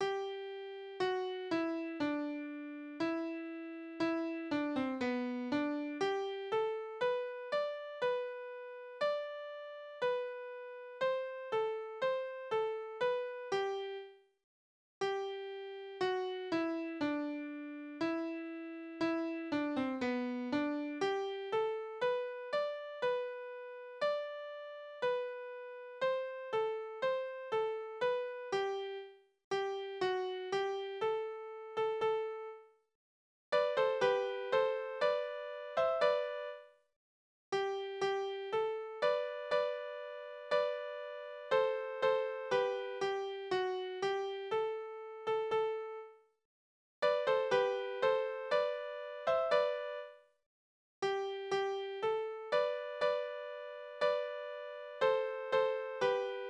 Naturlieder:
Tonart: G-Dur
Taktart: 2/4
Tonumfang: Oktave, Quarte
Besetzung: vokal
Anmerkung: im zweiten Teil zweistimmig; Takte 8-10 anderer Takt (4/4); Takt 22 anderer Takt (3/4)